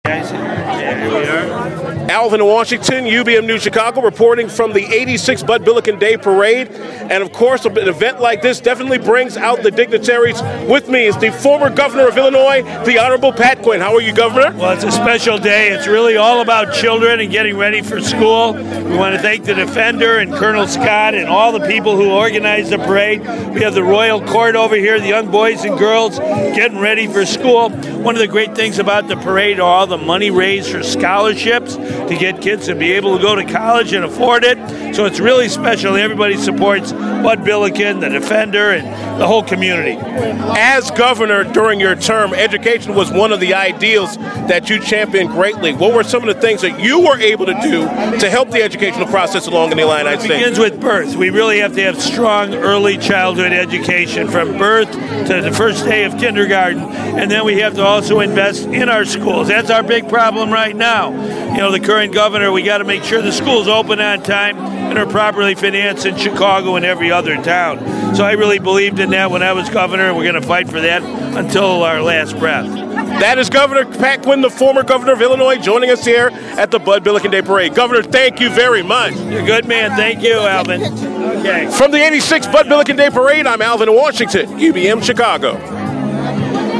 UBM News speaks with former IL Governor Pat Quinn at the Bud Billiken Parade